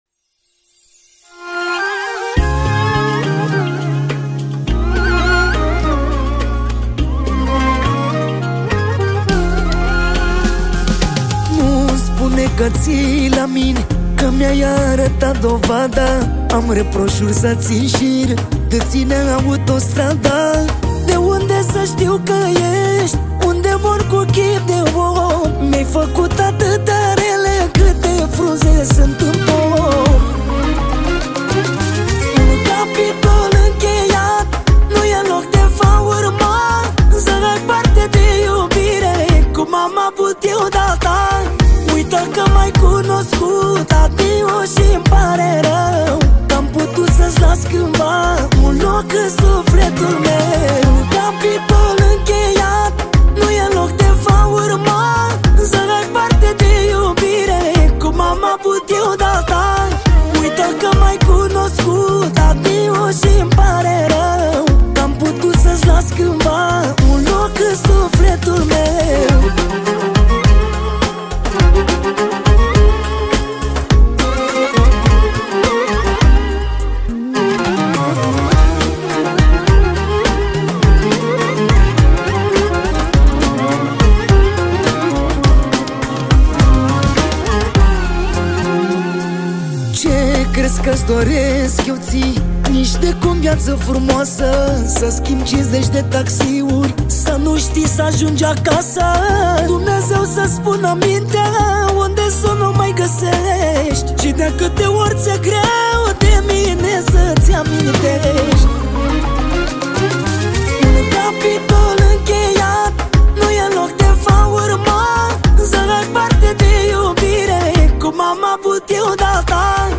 Data: 20.10.2024  Manele New-Live Hits: 0